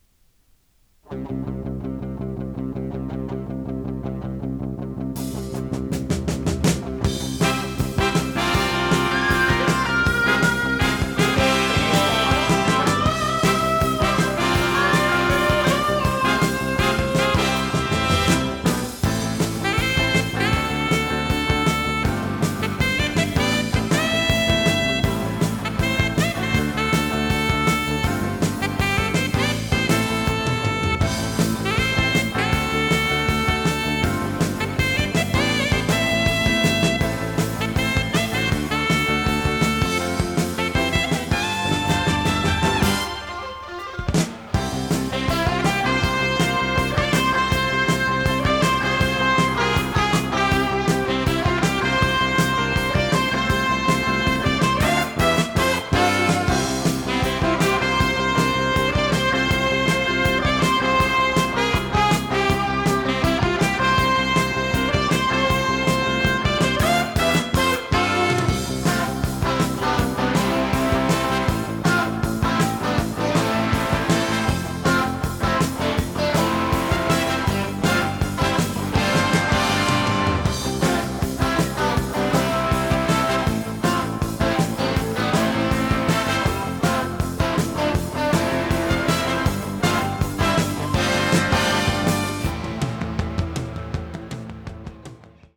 テープ：マクセルUDⅠ(1987年)
ノイズリダクションOFF
【フュージョン・ロック】48kHz-24bit 容量26.2MB